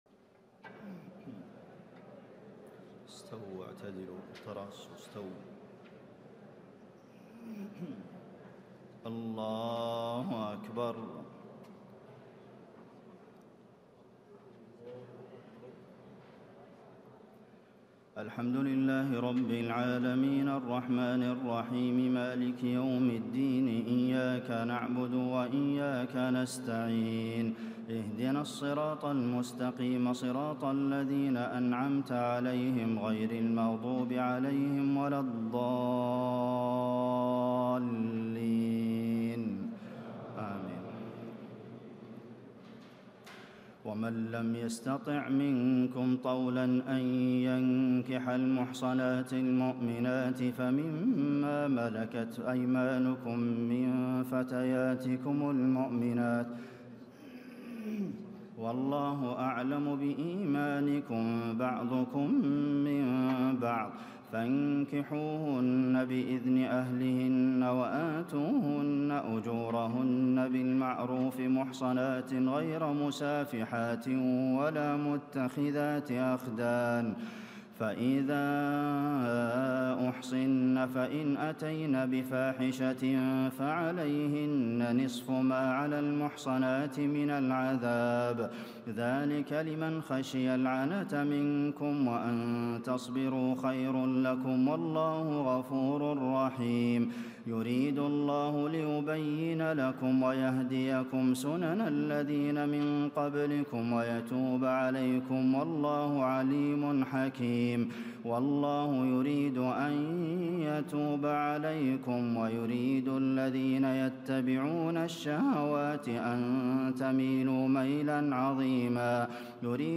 تهجد ليلة 25 رمضان 1436هـ من سورة النساء (25-87) Tahajjud 25 st night Ramadan 1436H from Surah An-Nisaa > تراويح الحرم النبوي عام 1436 🕌 > التراويح - تلاوات الحرمين